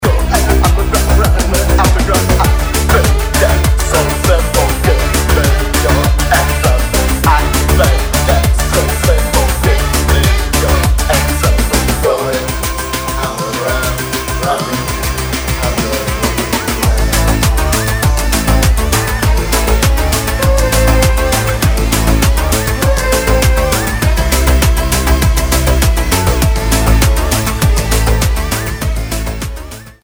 シンセ・ディスコ/エレクトロ度が格段に上昇、よくぞここまで違和感無く手を加えられたな、という仕上がり。